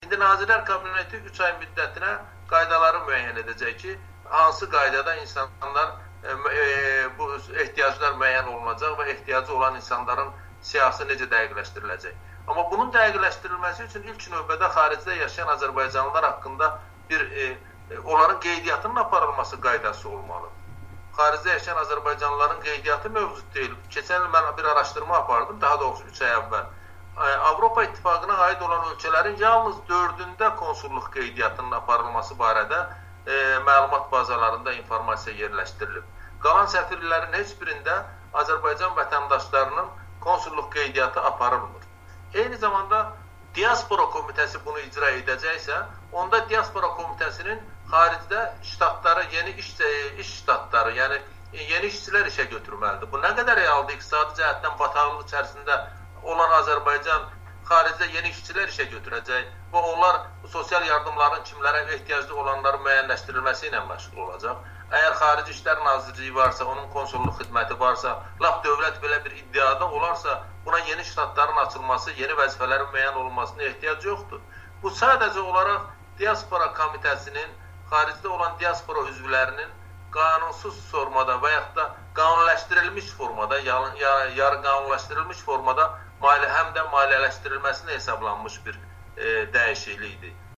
Xaricdə yaşayan azərbaycanlılar da sosial yardım alacaq [müsahibə]